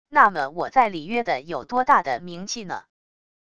那么我在里约的有多大的名气呢wav音频生成系统WAV Audio Player